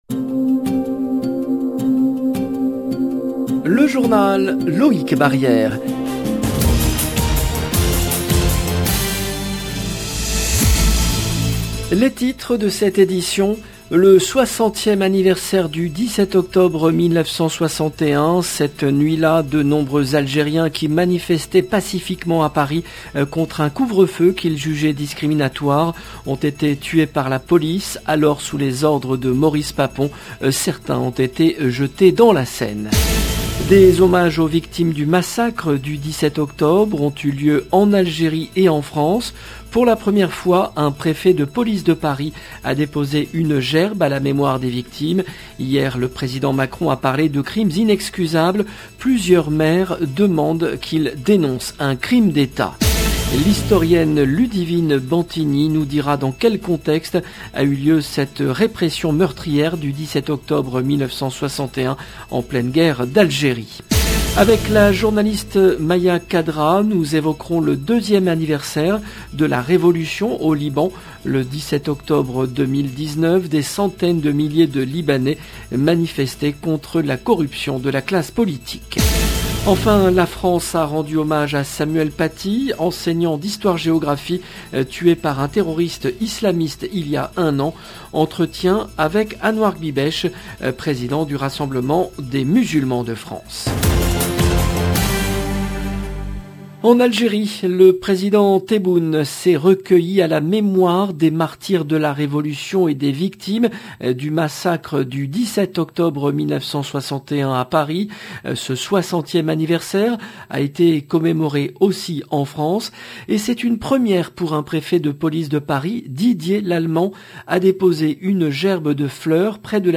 Le journal du soir en langue française 17/10/21
Entretien avec Anouar Kbibech, président du Rassemblement des Musulmans de France. 0:00 17 min 41 sec